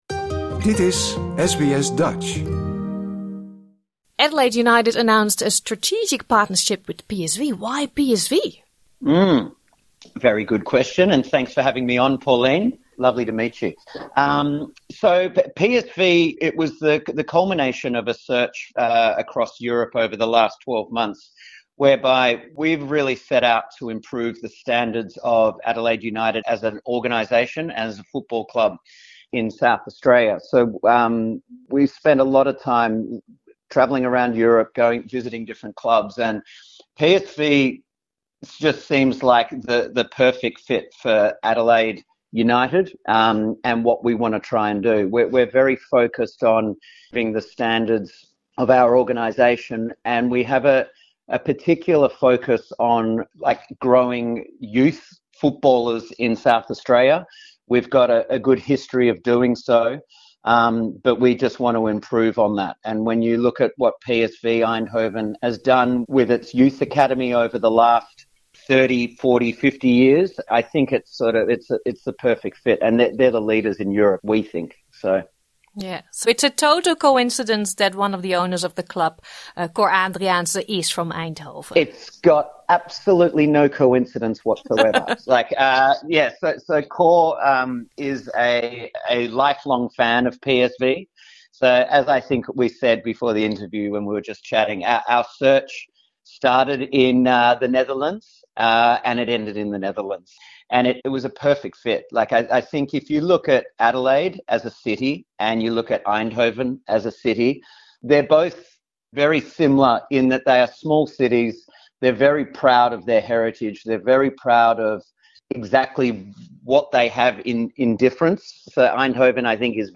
Dit interview is in het Engels.